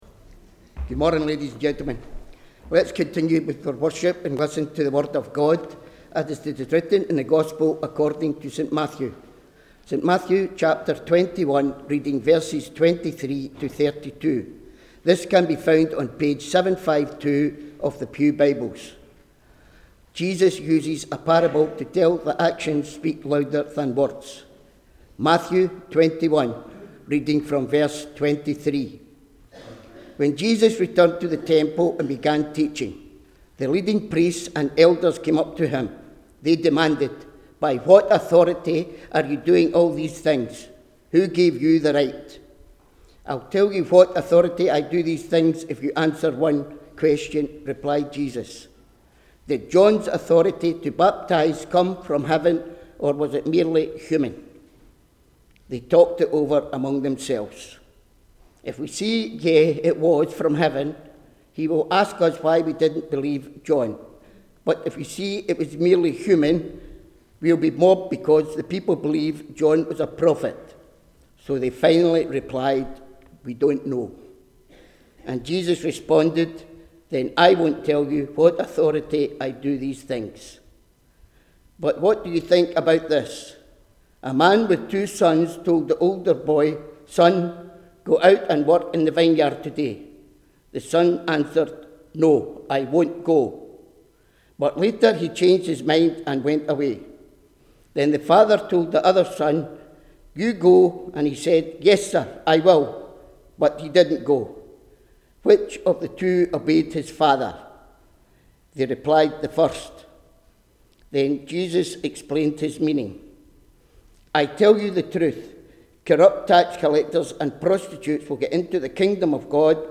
The Reading prior to the Sermon were taken from Matthew 21: 23-32 (NLT)